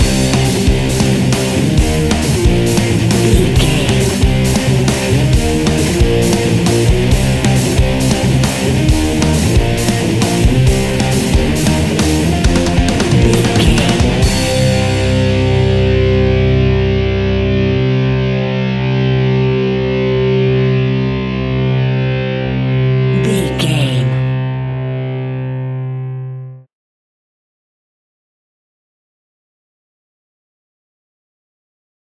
Epic / Action
Aeolian/Minor
hard rock
heavy rock
blues rock
distortion
rock instrumentals
rock guitars
Rock Bass
Rock Drums
heavy drums
distorted guitars
hammond organ